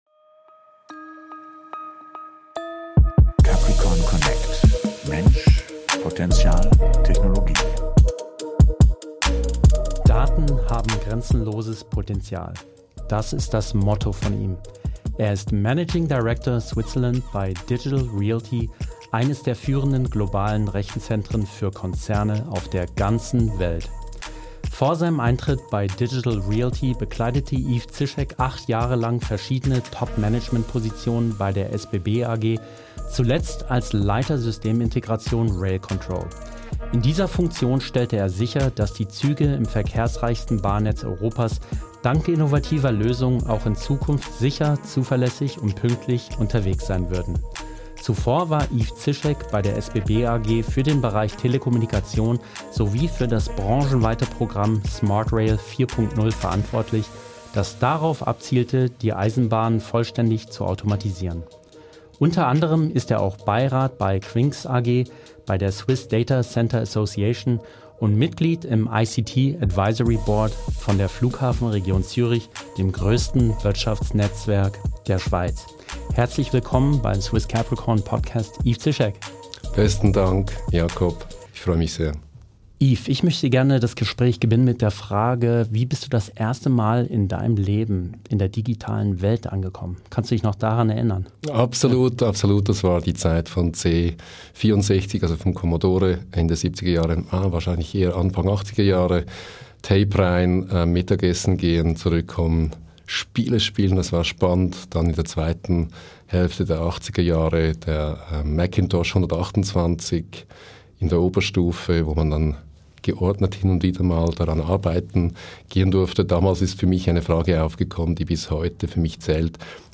#35 - Interview mit